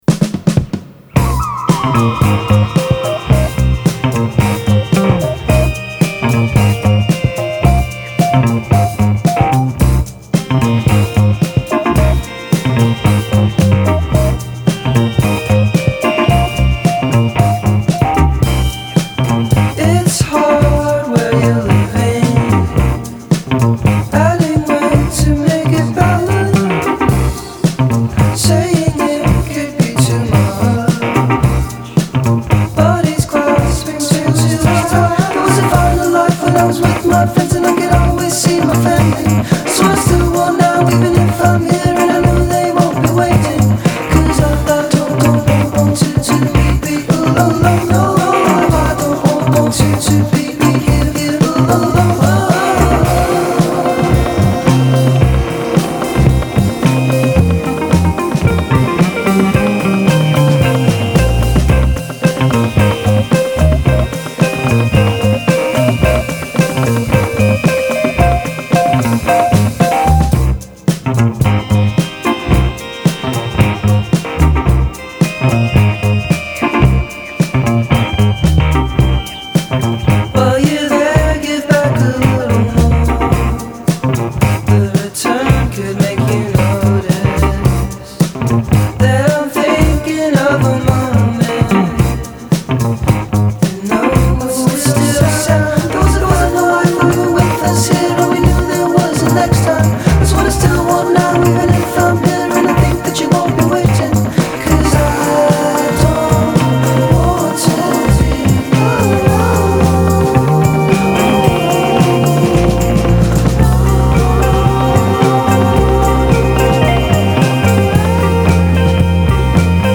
sempre più Jamiroquai